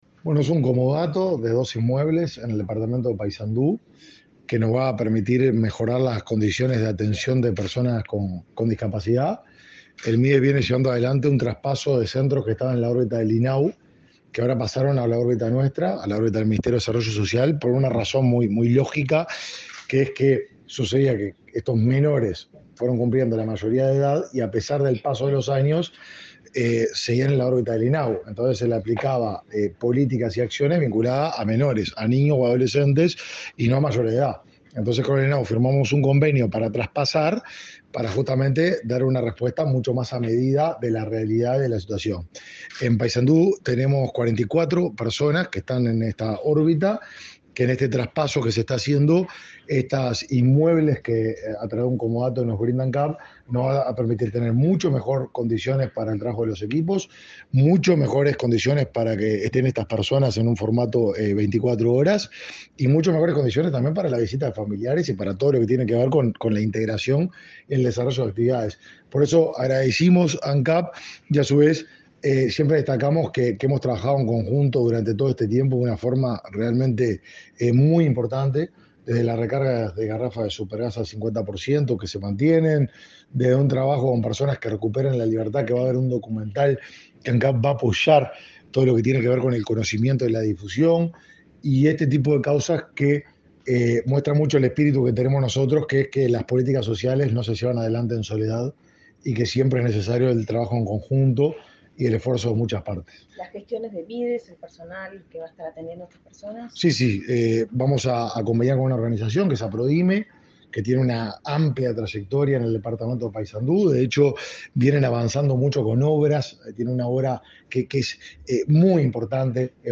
Declaraciones del ministro de Desarrollo Social, Martín Lema
El ministro de Desarrollo Social, Martín Lema, dialogó con la prensa, durante el acto de firma de un contrato de comodato con el vicepresidente de